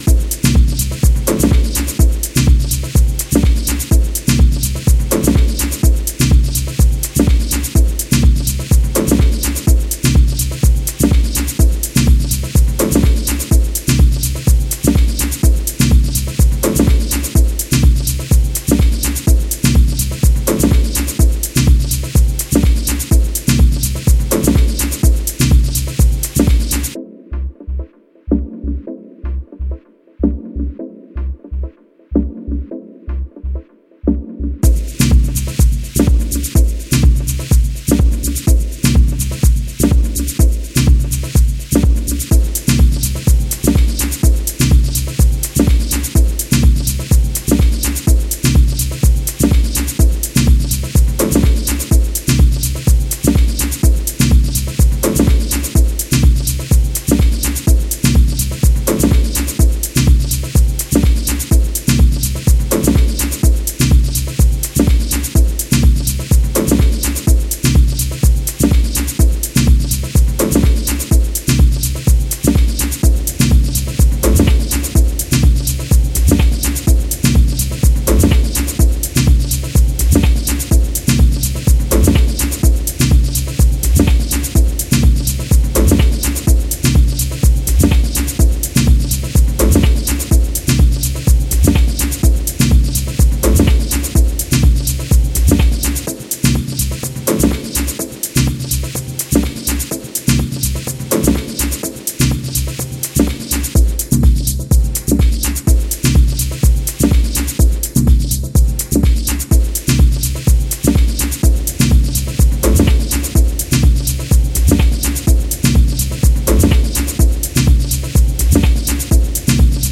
Techno Dub Techno